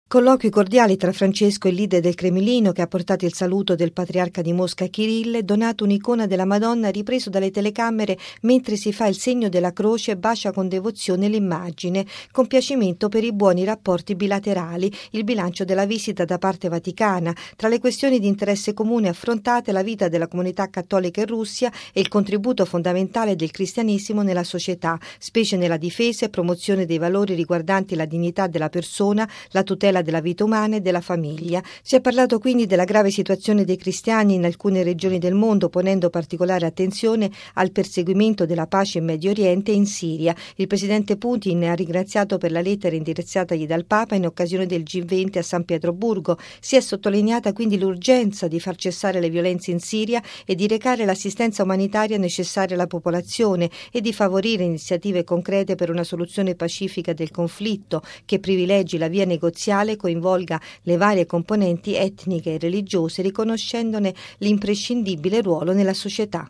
◊   La grave situazione in Siria, la pace in Medio Oriente ed il ruolo fondamentale dei cristiani nella società al centro del colloquio tra Papa Francesco ed il presidente russo Putin, ricevuto ieri pomeriggio, per la quarta volta in Vaticano, dopo le due udienze nel 2000 e 2003 con Giovanni Paolo II e nel 2007 con Benedetto XVI. Il servizio